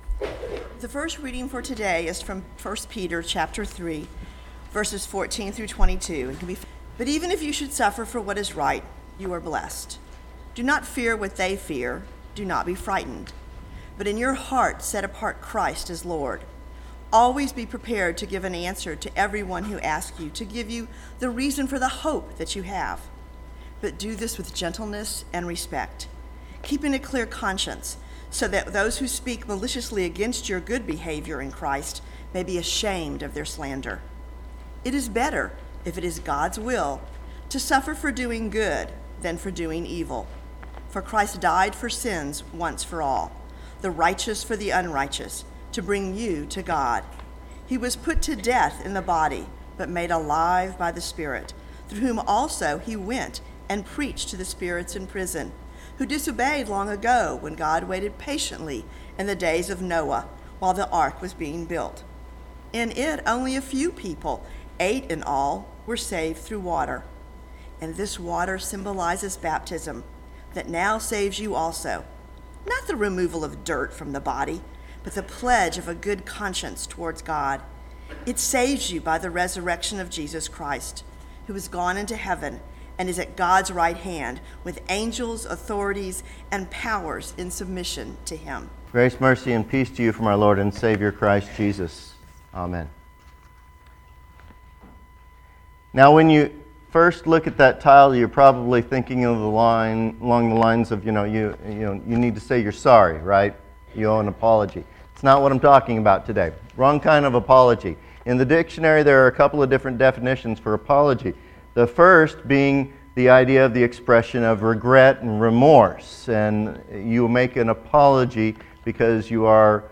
Sermons - Holy Cross Lutheran Church